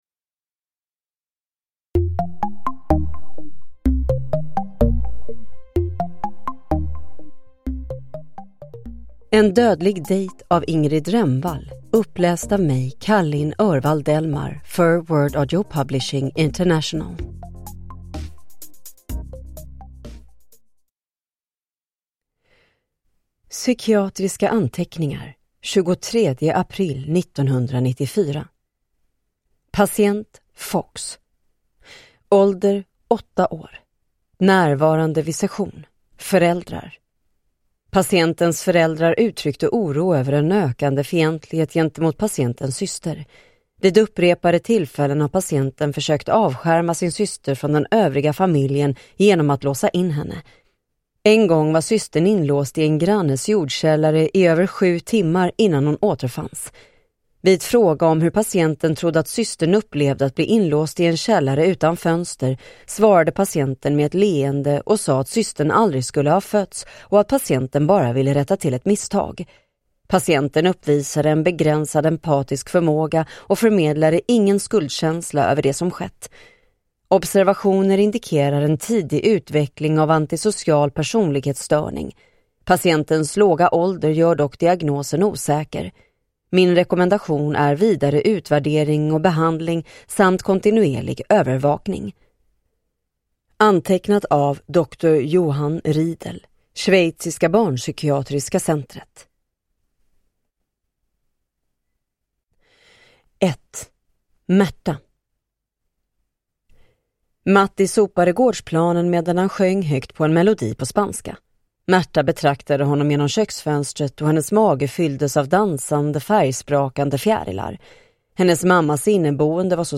En dödlig dejt – Ljudbok